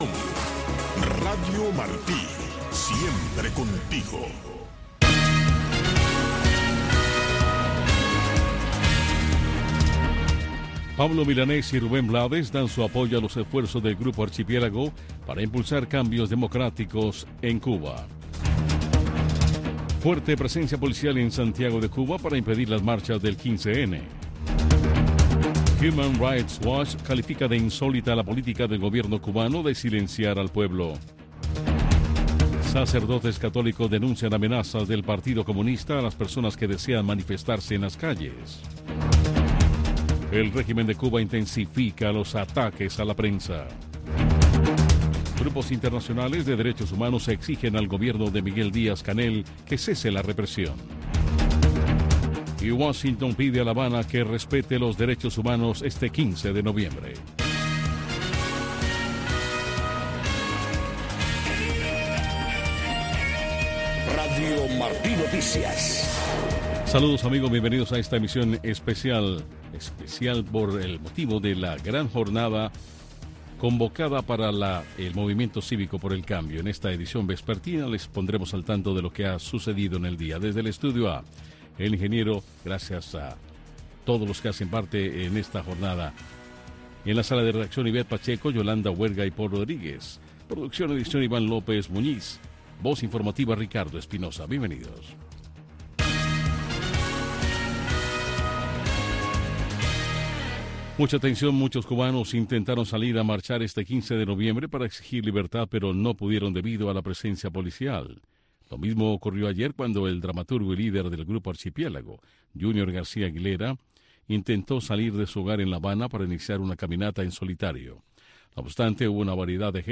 Noticiero de Radio Martí 6:00 PM